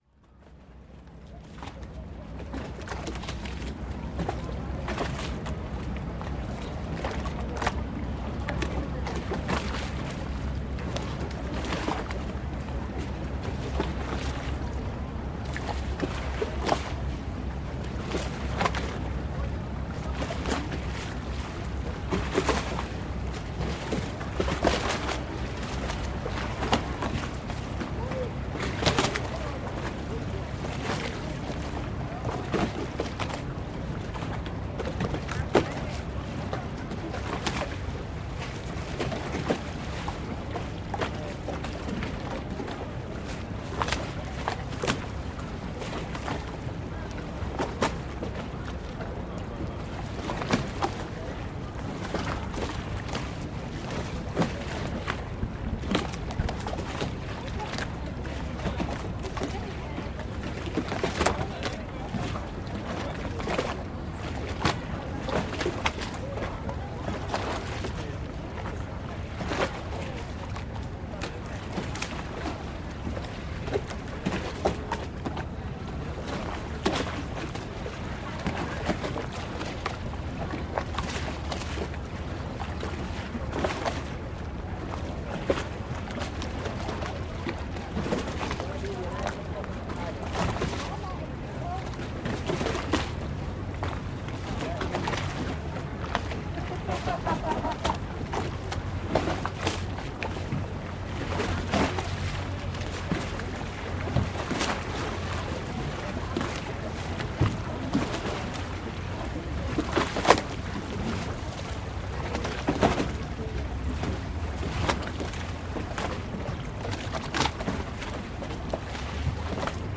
Gondolas near St. Mark's Square, Venice
Moored gondolas slosh on the waves as boats pass, close to St. Mark's Square, Venice.